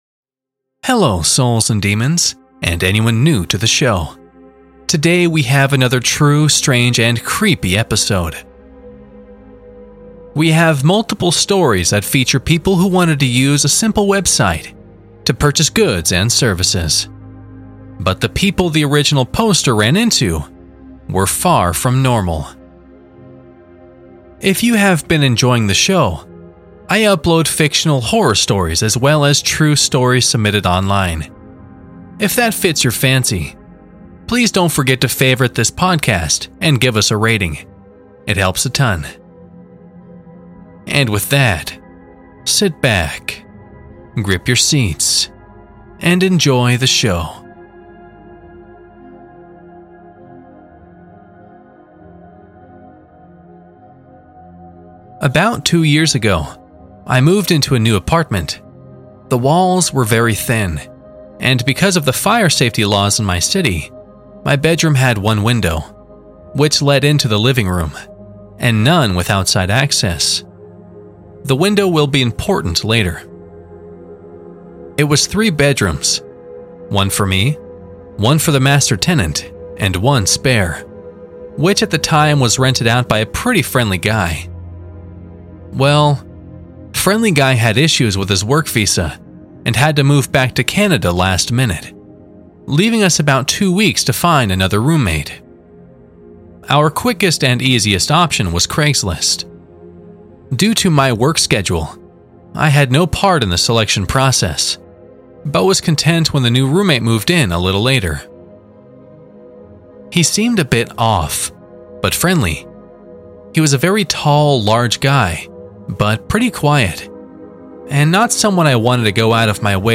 NEW HORROR NARRATIONS EVERY WEEK